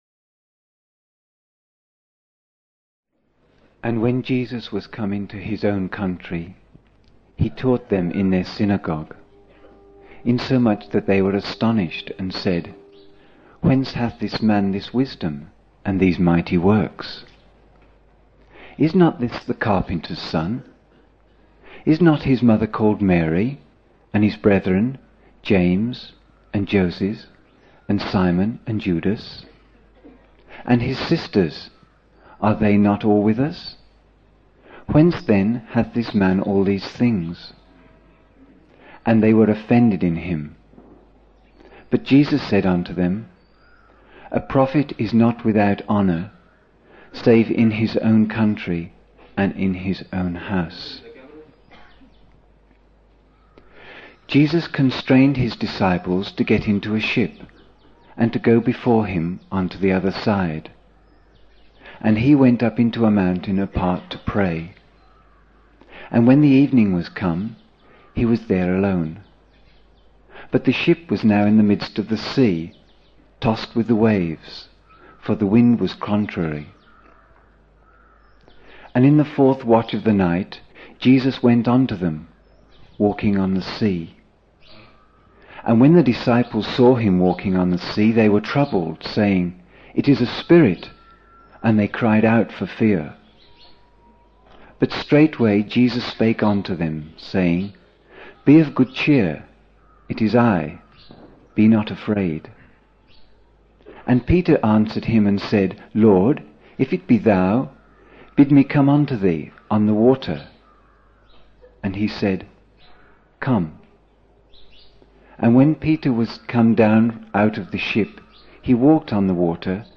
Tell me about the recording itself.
11 December 1975 (His Birthday) morning in Buddha Hall, Poona, India